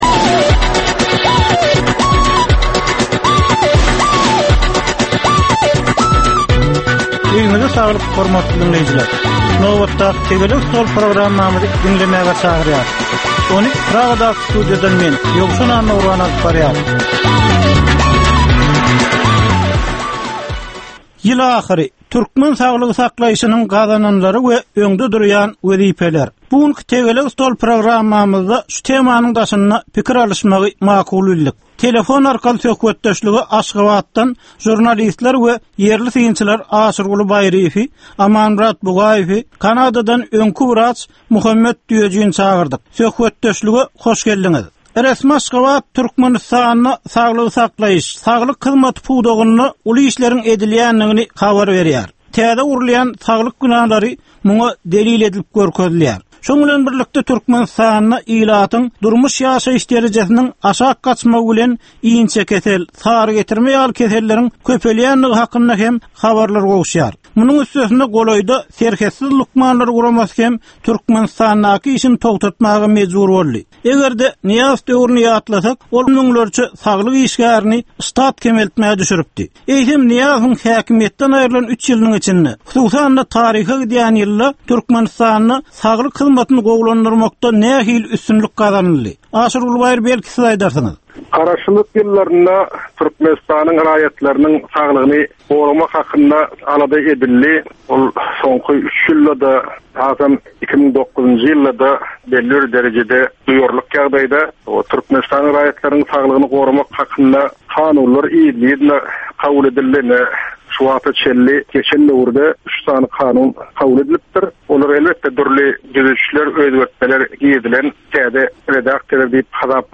Jemgyýetçilik durmuşynda bolan ýa-da bolup duran soňky möhum wakalara ýa-da problemalara bagyşlanylyp taýýarlanylýan ýörite Tegelek stol diskussiýasy. 25 minutlyk bu gepleşhikde syýasatçylar, analitikler we synçylar anyk meseleler boýunça öz garaýyşlaryny we tekliplerini orta atýarlar.